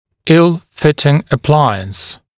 [ɪl-‘fɪtɪŋ ə’plaɪəns][ил-‘фитин э’плайэнс]плохо подогнанный аппарат, неудобный для носки аппарат